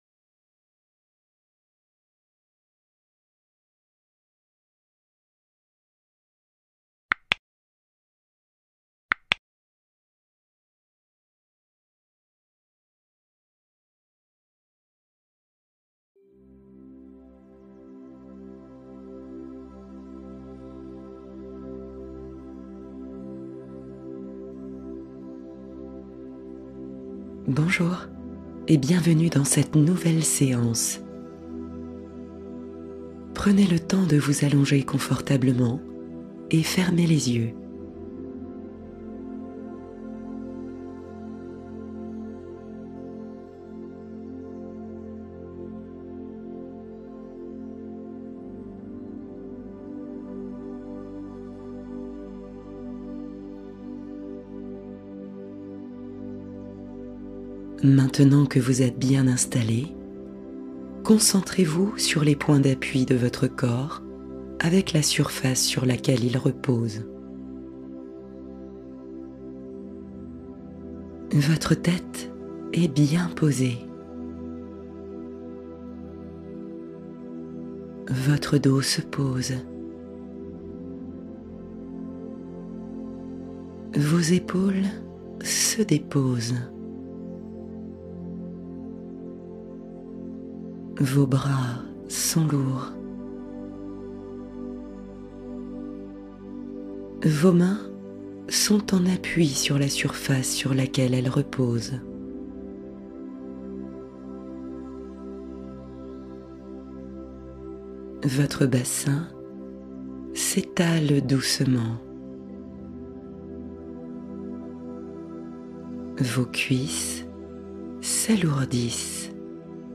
Régénération nerveuse profonde : détente mentale guidée longue